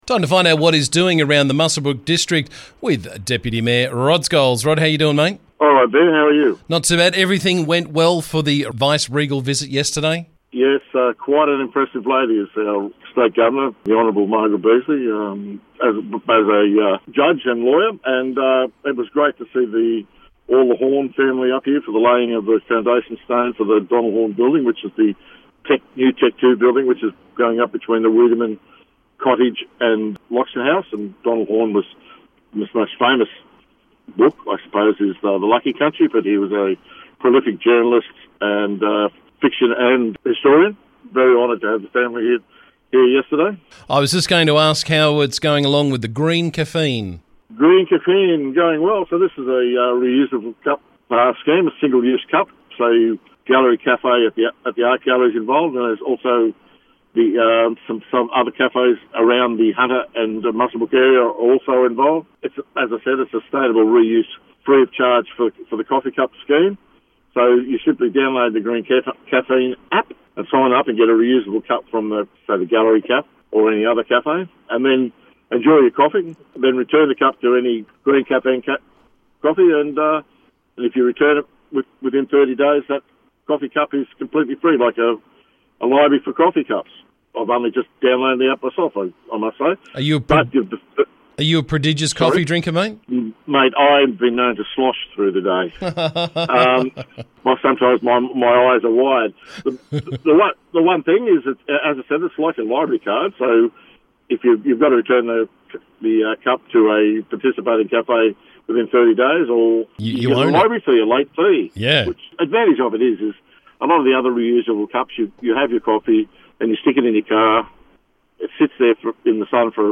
Muswellbrook Shire Council Deputy Mayor Rod Scholes joined me to talk about the latest from around the district.